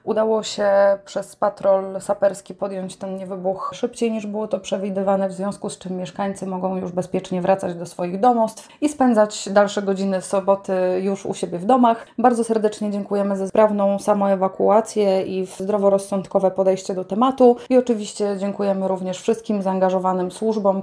Na dzisiaj zarządzona została ewakuacja okolicznych mieszkańców, zamieszanie trwało jednak znacznie krócej niż zakładano, bo jak informuje wiceburmistrz Magdalena Bauer – Styborska, alarm został odwołany przed południem.